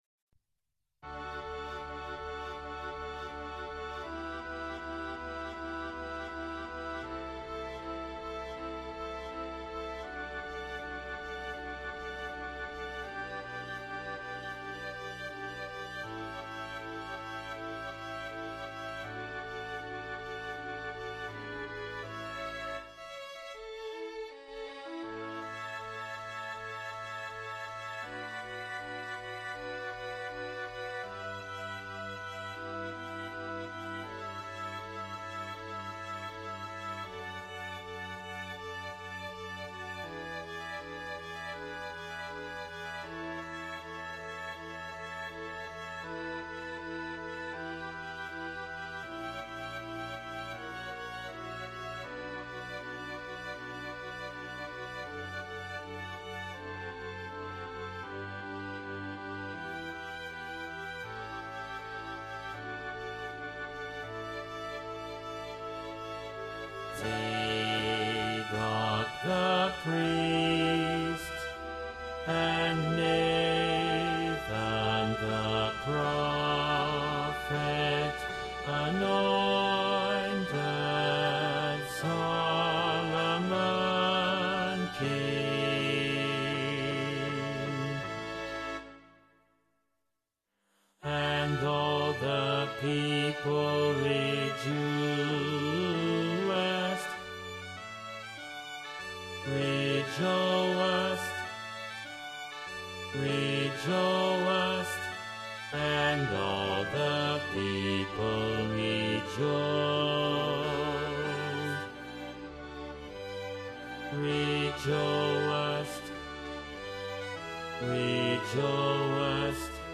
Ténors
Emphasised voice and other voices